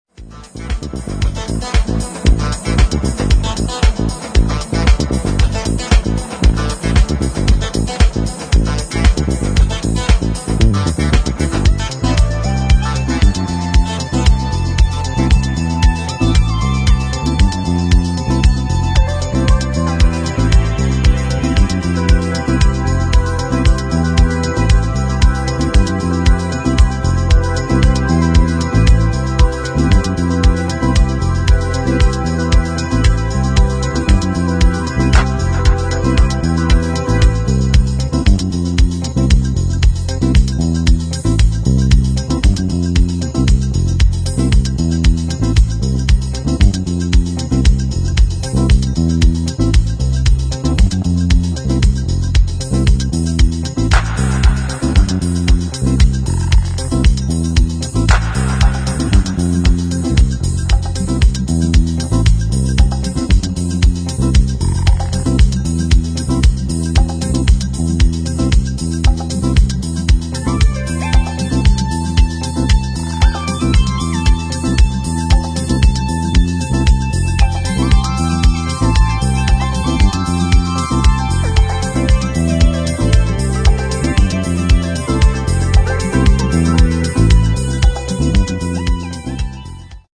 [ DEEP HOUSE / JAZZ / CROSSOVER ]